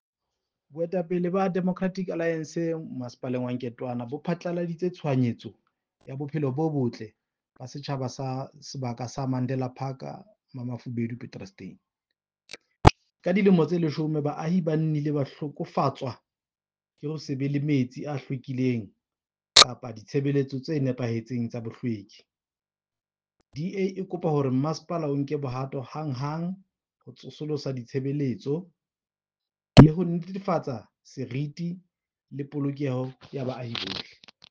Sesotho soundbites by Cllr Diphapang Mofokeng.